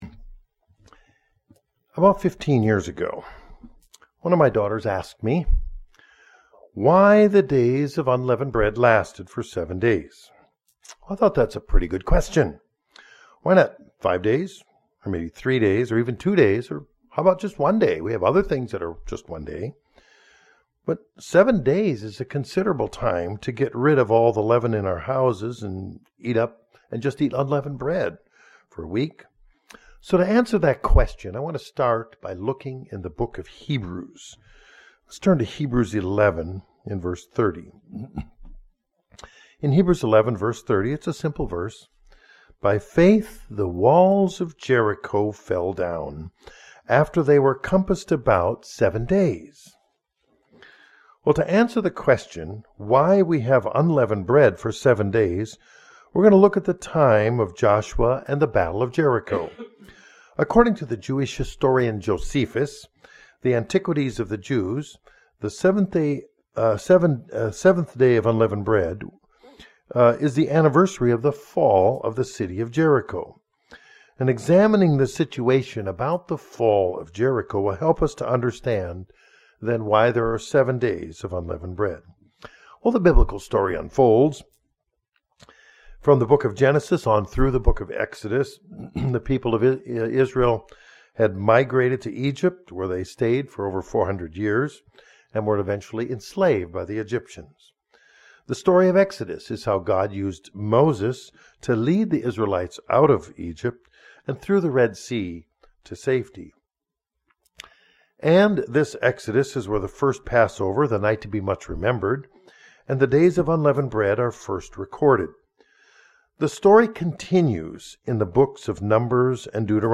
Sermon
Given in Northwest Arkansas